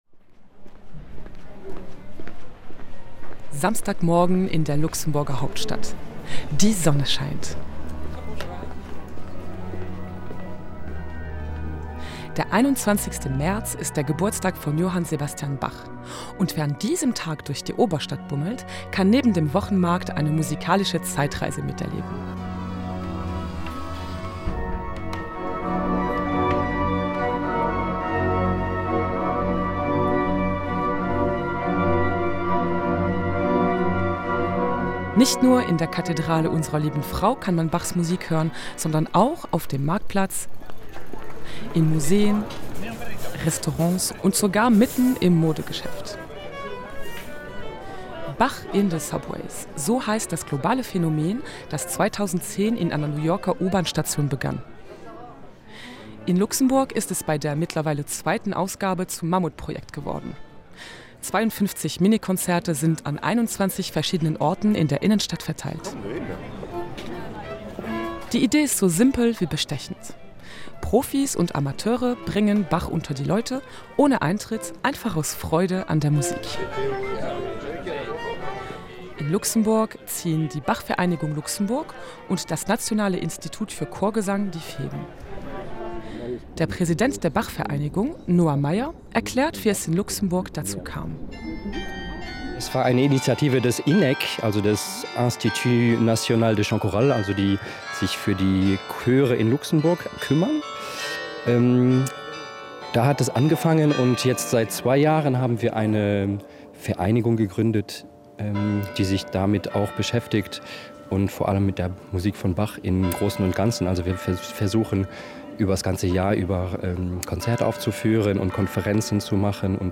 Wenn Bach in den Kleiderladen kommt: In Luxemburg erklingt Bach jenseits des Konzertsaals
So auch am vergangenen Wochenende in Luxemburg. Zu Bachs Geburtstag zogen Musikerinnen und Musiker durch die Stadt, um seine Musik an ungewöhnlichen Orten zu spielen.